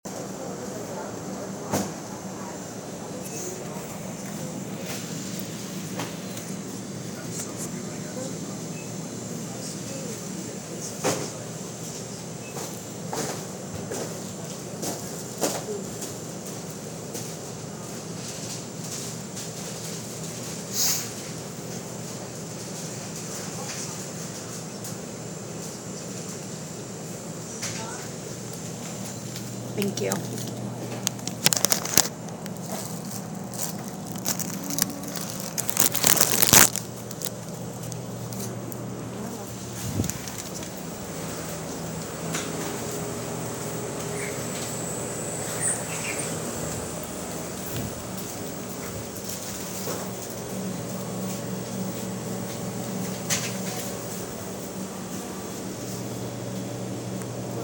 Field Recording 11
Location: Dutch on the North Side of campus
Sounds: People talking softly, paper crinkling, music playing.